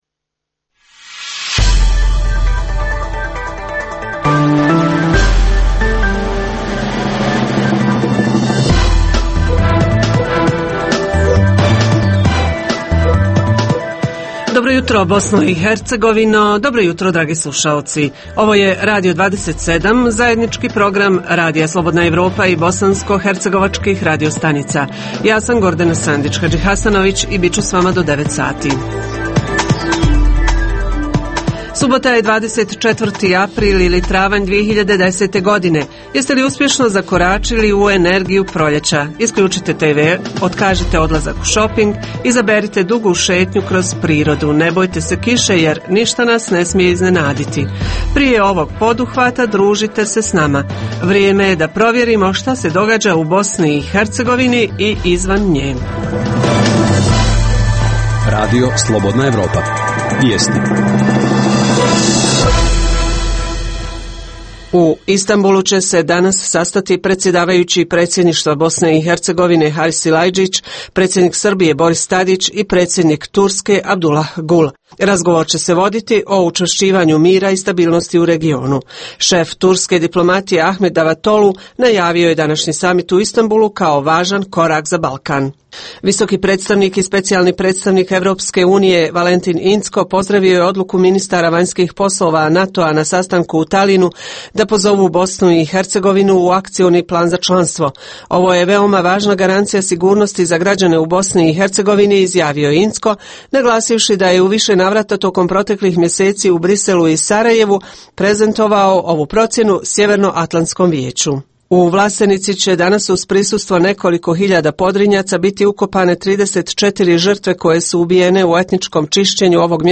Kako grad Sarajevo pomaže rad javnih kuhinja u kojim je "sve više gladnih usta"? Uz najnovije vijesti i dobar izbor muzike, budite uz Jutranji program "Radio 27" !!